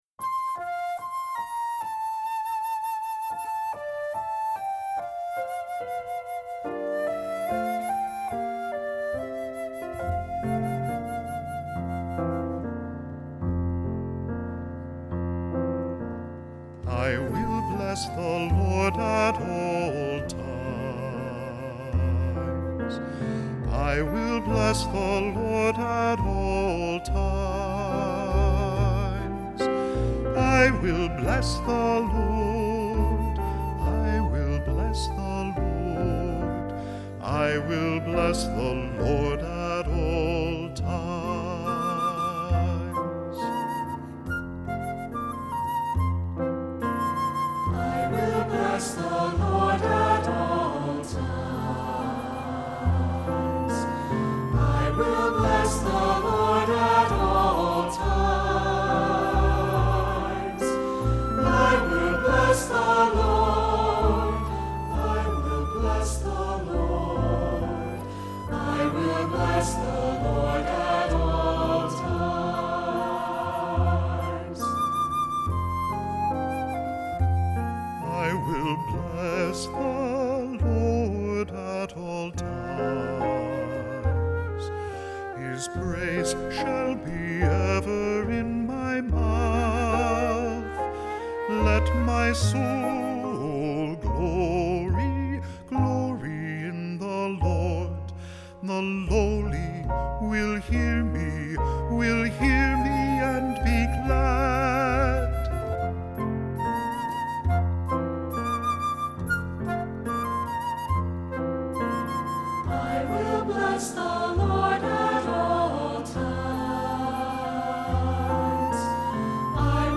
Voicing: SAB; Cantor; Solo; Assembly